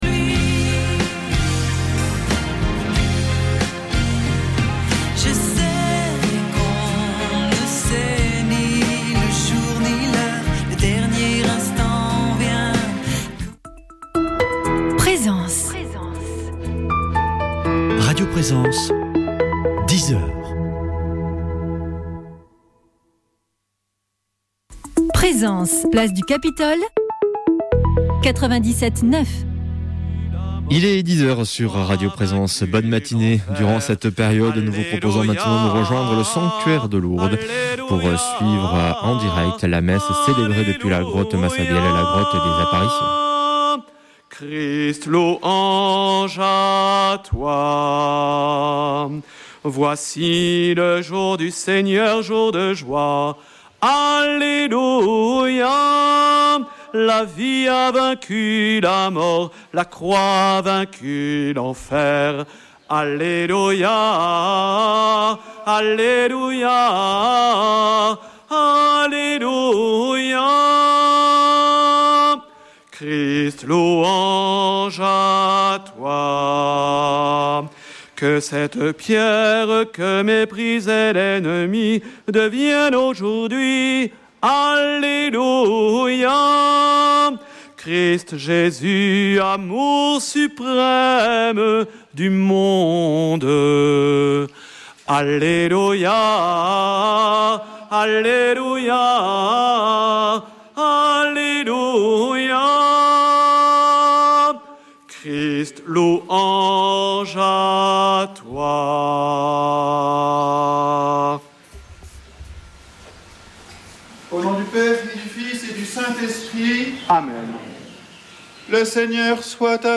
Messe depuis le sanctuaire de Lourdes du 12 mai